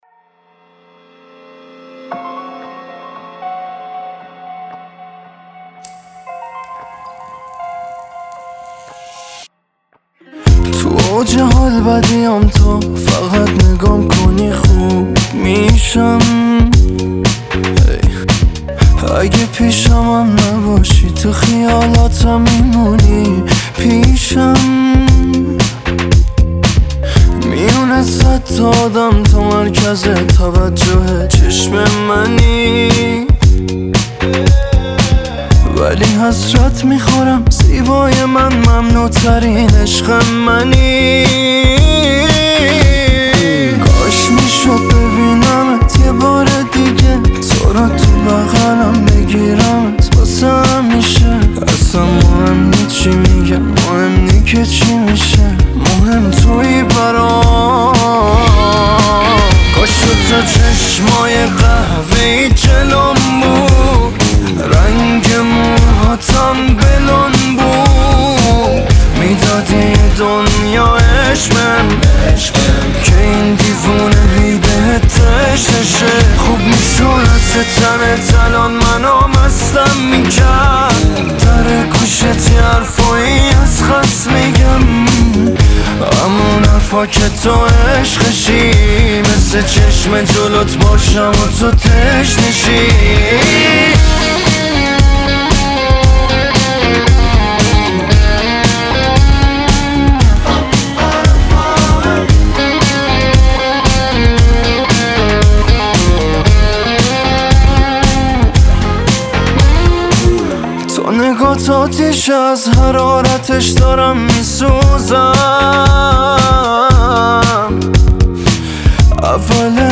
پاپ
آهنگ رپ